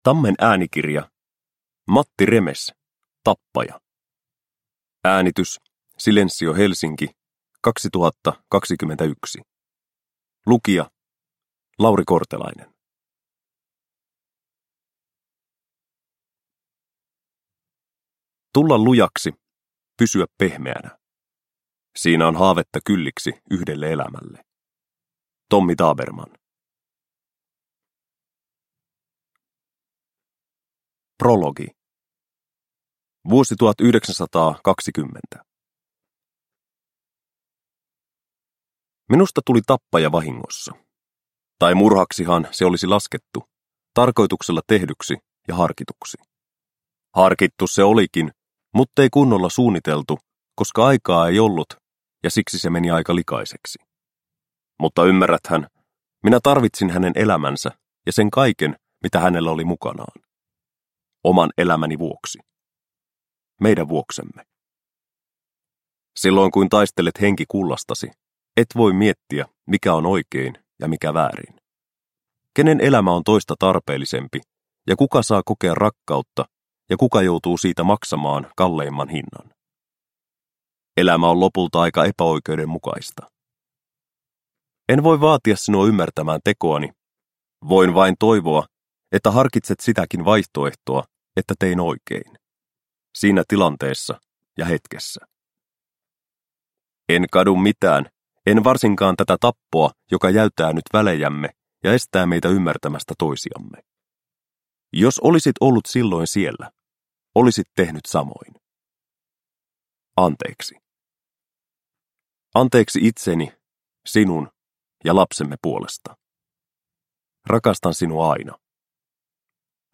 Tappaja – Ljudbok – Laddas ner